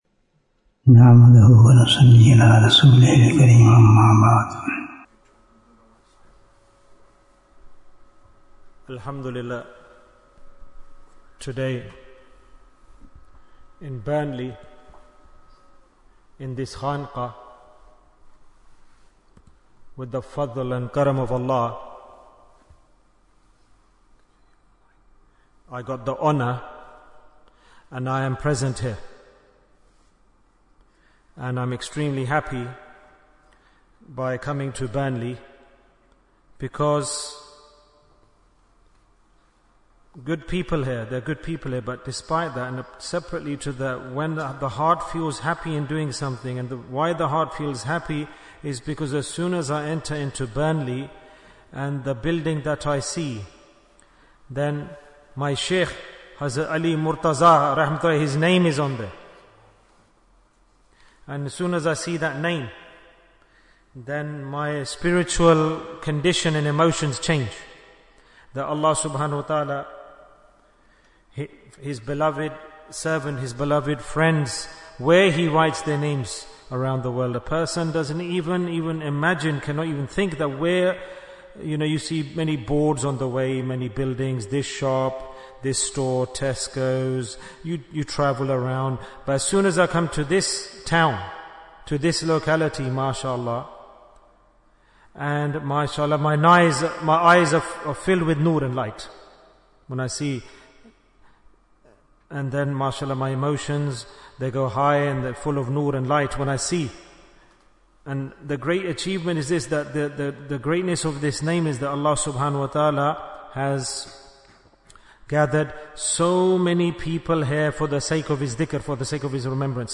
Jewels of Ramadhan 2025 - Episode 30 - The Last Asharah in Burnley Bayan, 46 minutes22nd March, 2025